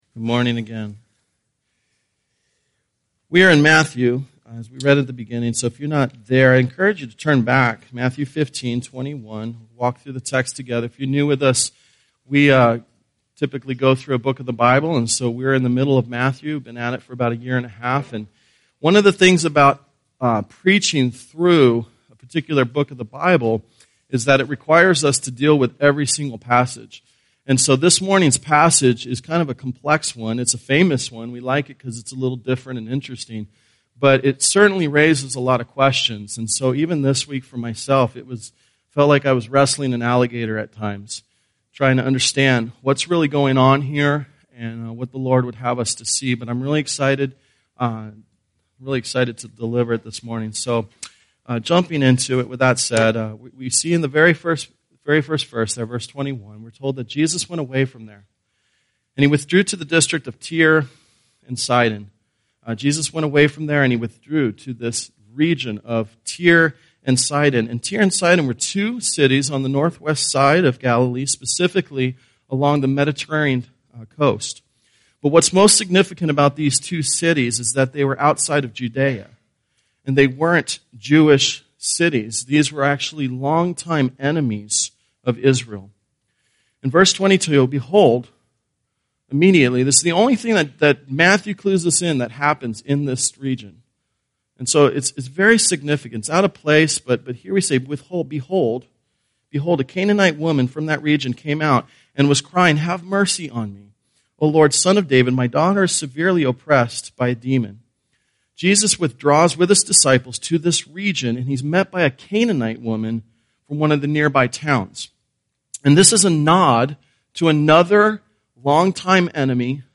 Sunday Worship
Tagged with Sunday Sermons